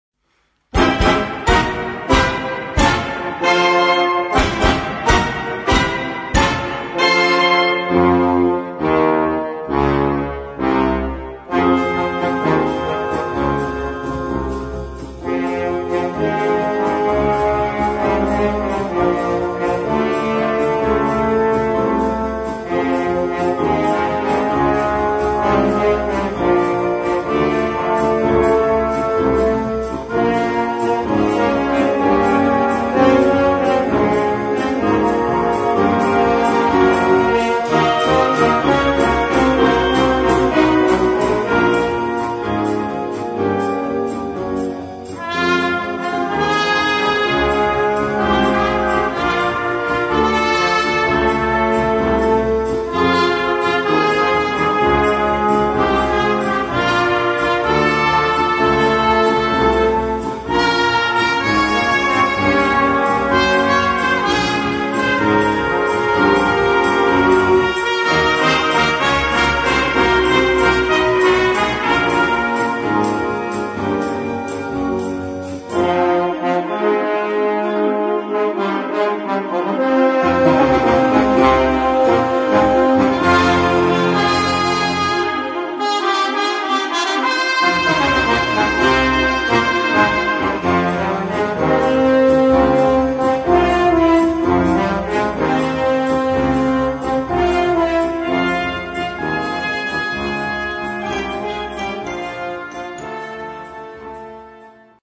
Partitions pour orchestre d'harmonie et fanfare.